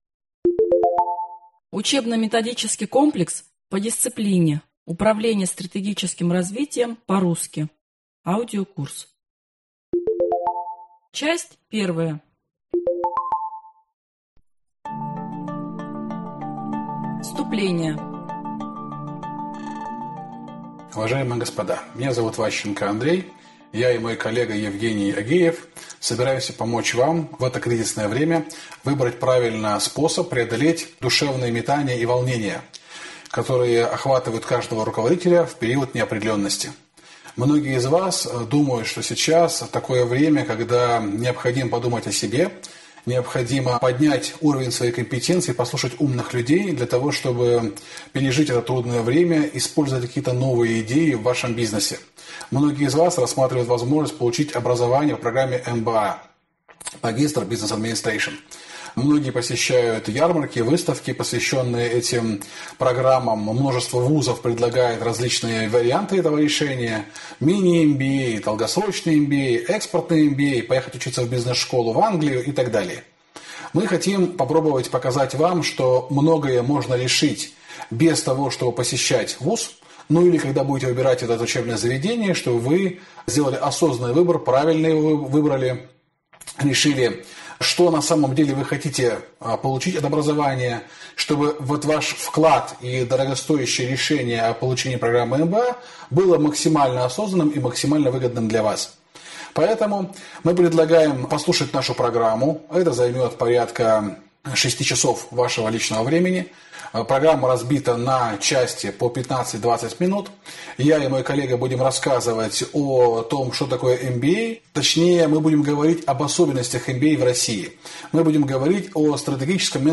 Аудиокнига Управление стратегическим развитием «по-русски» | Библиотека аудиокниг